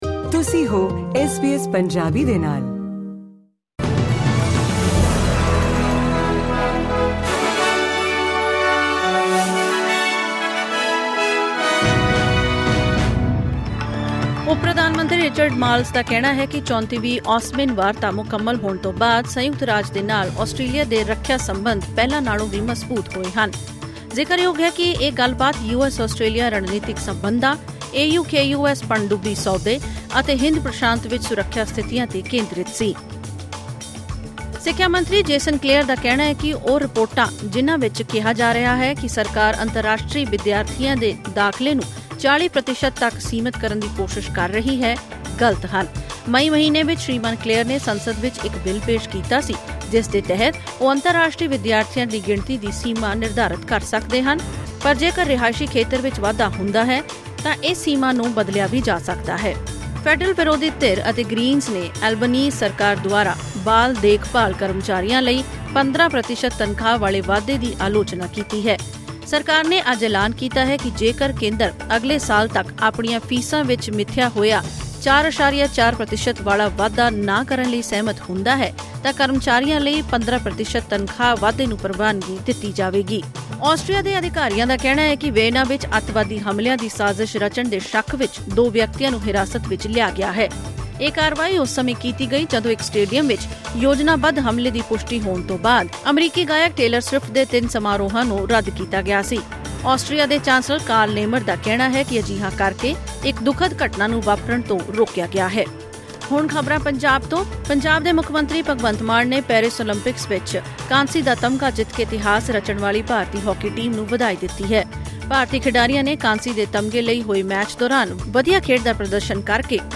ਐਸ ਬੀ ਐਸ ਪੰਜਾਬੀ ਤੋਂ ਆਸਟ੍ਰੇਲੀਆ ਦੀਆਂ ਮੁੱਖ ਖ਼ਬਰਾਂ: 9 ਅਗਸਤ 2024